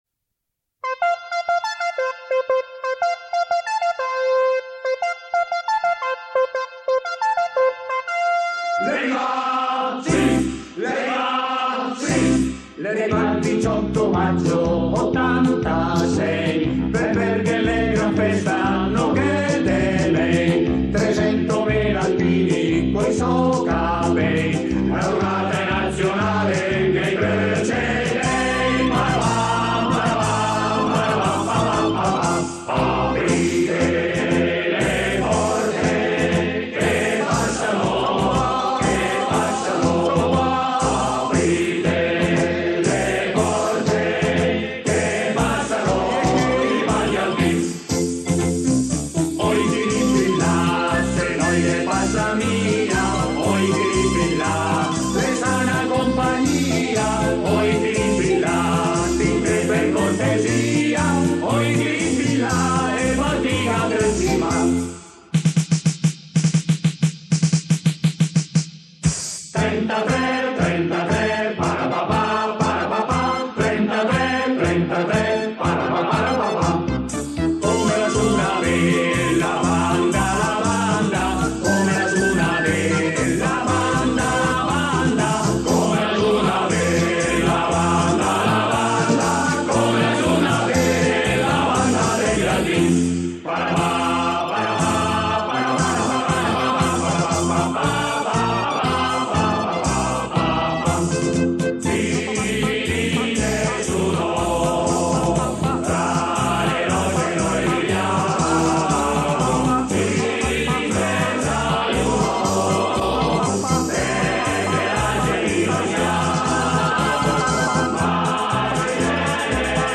fantasia di canti alpini
(in occasione della 59^ Adunata Nazionale